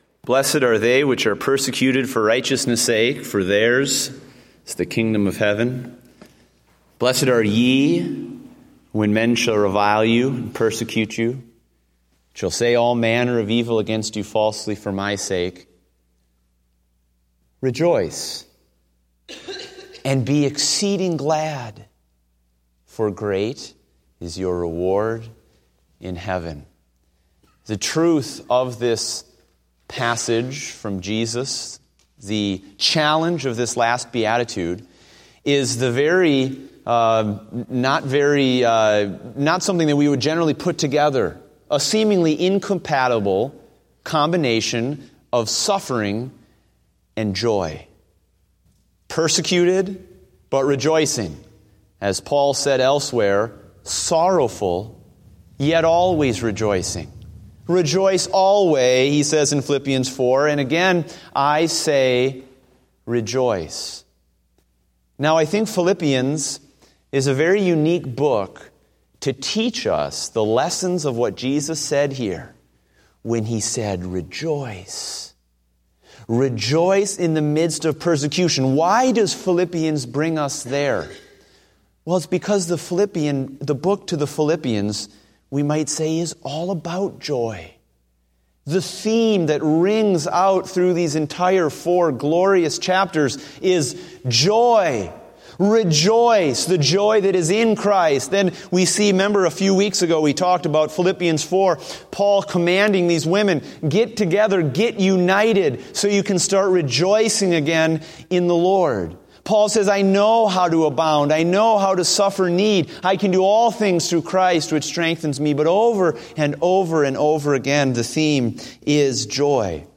Date: October 11, 2015 (Evening Service)